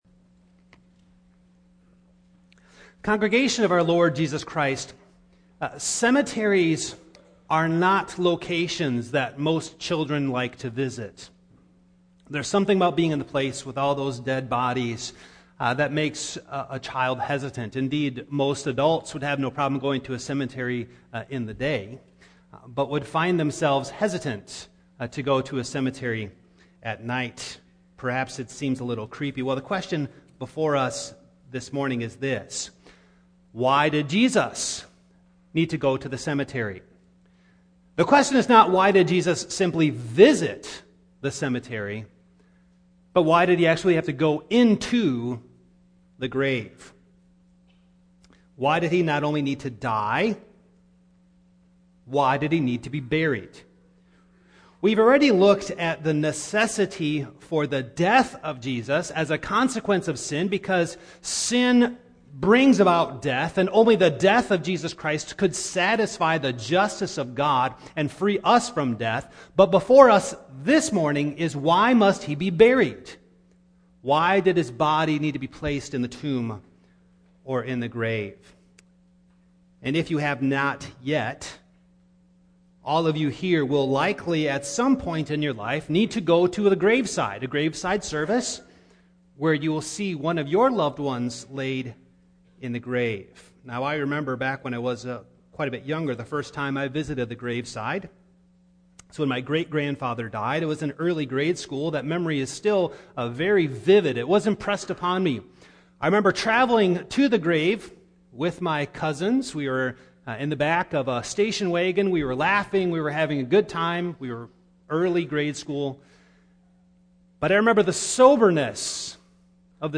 Passage: Matthew 12:38-42 Service Type: Morning